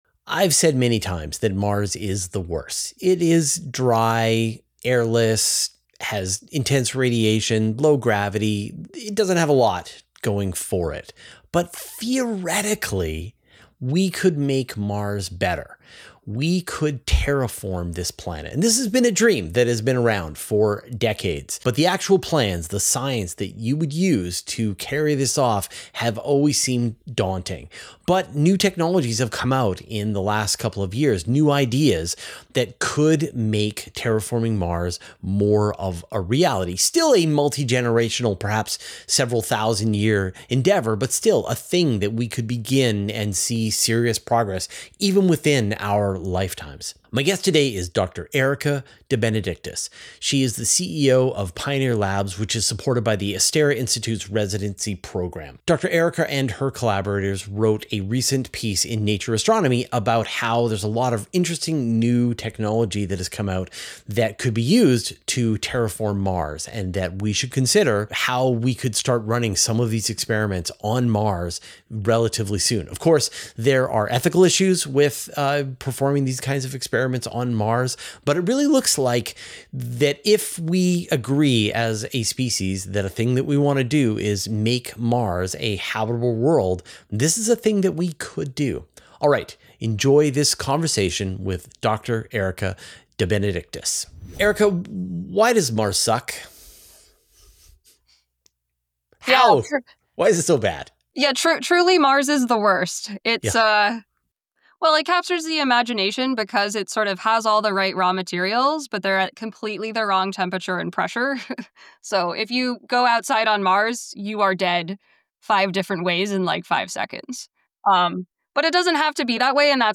[Interview] 3 Key Technologies to Start Terraforming Mars in Our Lifetime